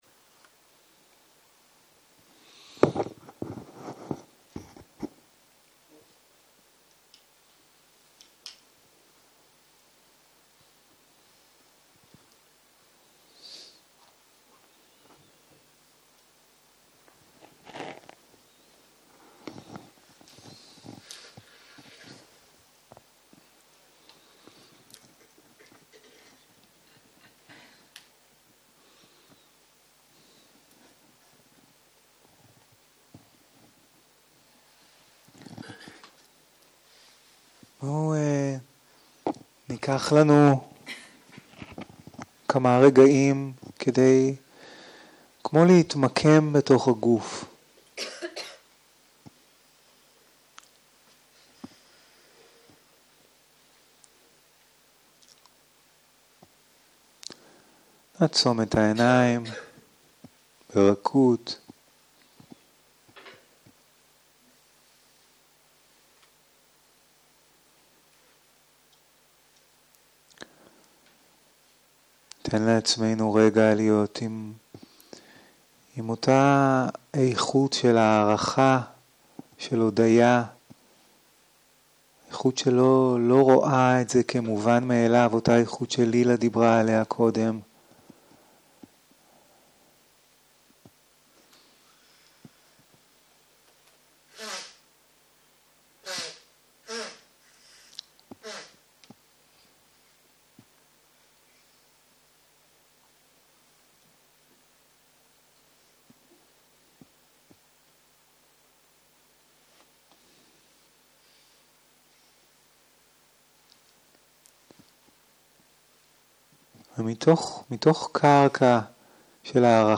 יום 1 - ערב - הנחיות מדיטציה - תשומת לב לנשימה - הקלטה 1